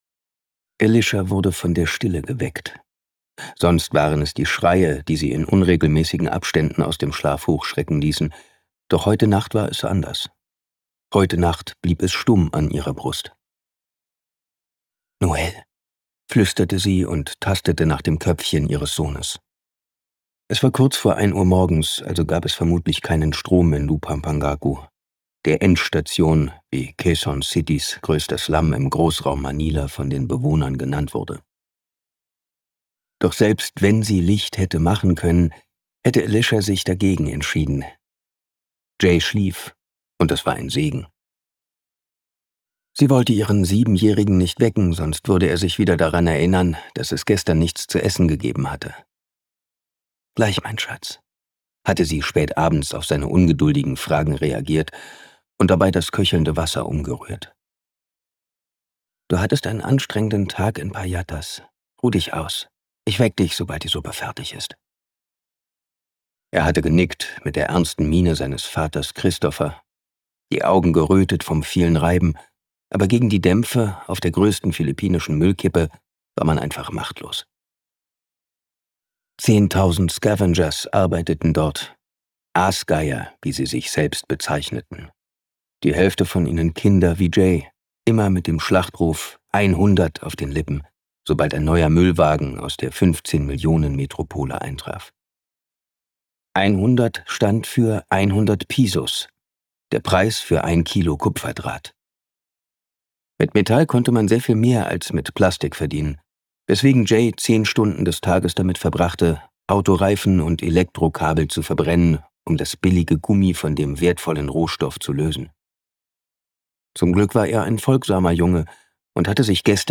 Das Hörbuch Noah von Bestsellerautor Sebastian Fitzek ist ein packender Psychothriller, der dich auf eine atemlose Jagd nach Identität, Wahrheit und Überleben schickt.
Seine markante Stimme hat die Hörbücher entscheidend geprägt und ihren großen Erfolg maßgeblich mitbegründet.
Gekürzt Autorisierte, d.h. von Autor:innen und / oder Verlagen freigegebene, bearbeitete Fassung.
Noah Gelesen von: Sebastian Fitzek, Simon Jäger
• Sprecher:innen: Simon Jäger